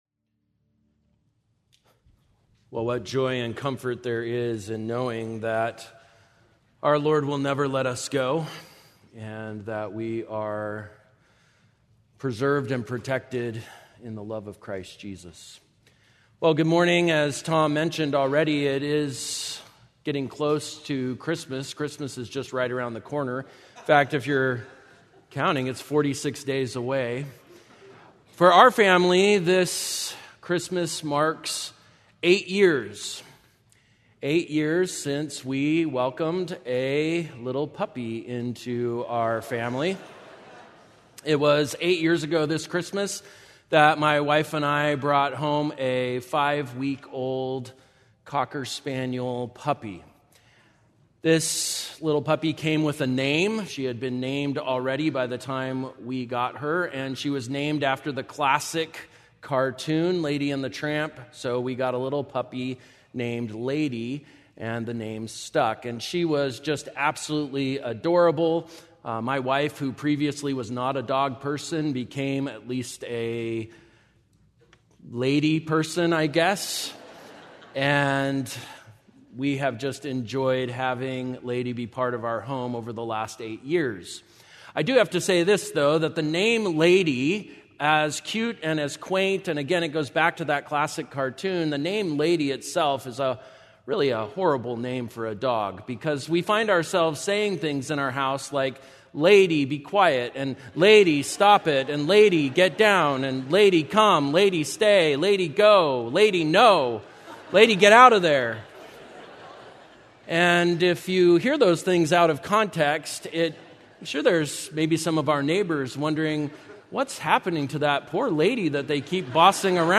Stevenson Ranch Bible Study November 9